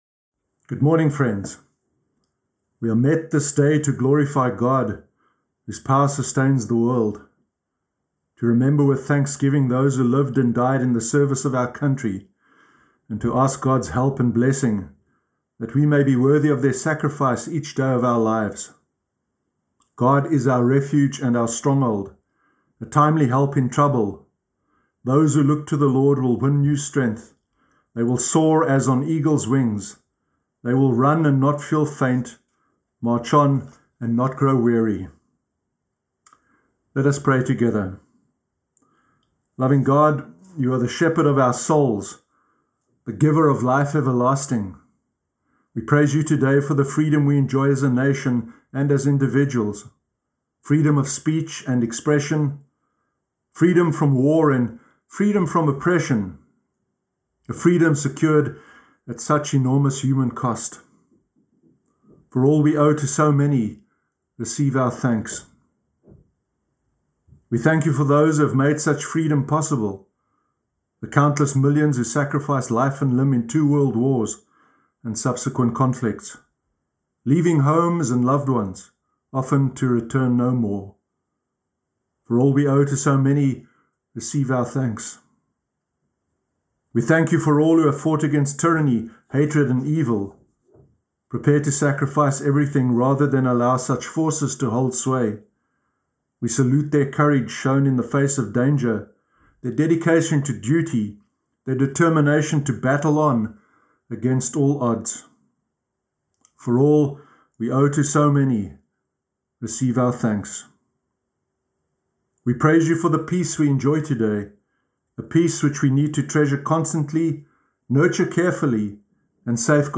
Sermon Sunday 7 November 2020
sermon-sunday-8-november-2020.mp3